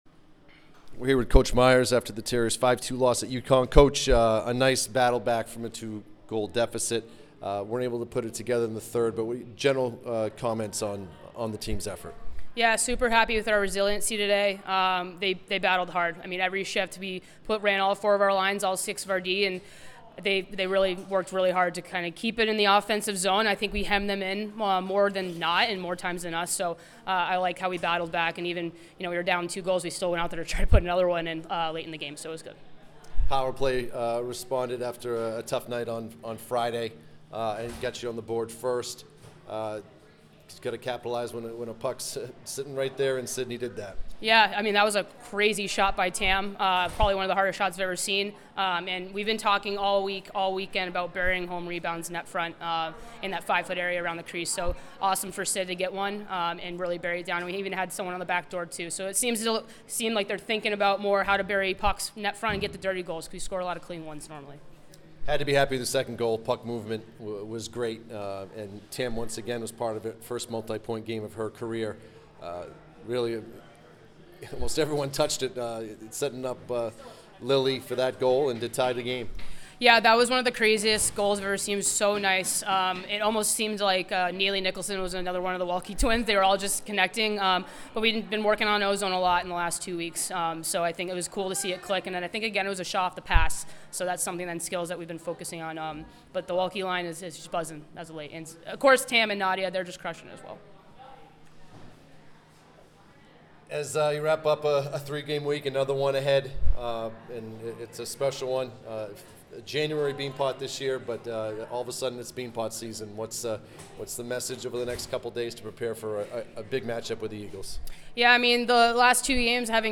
UConn Postgame Interview